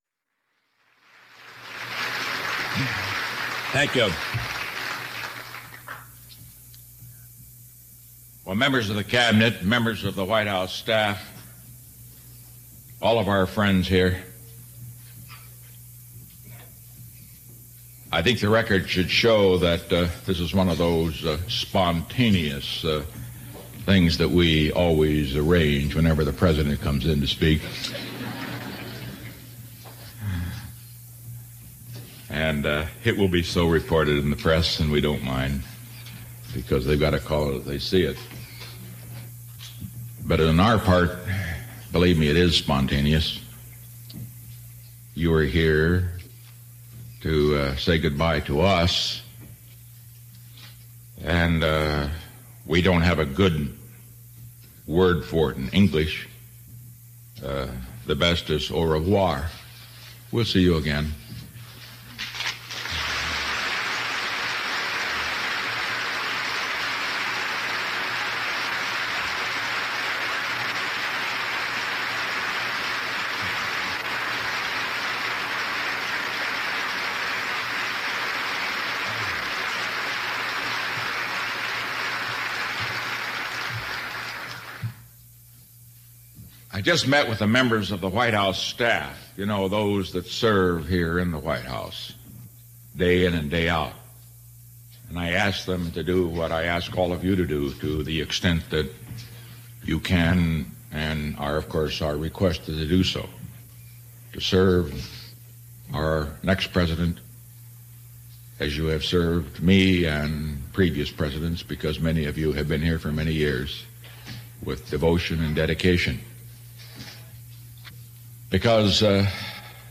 American Rhetoric: Richard Nixon - Farewell Address to White House Cabinet and Staff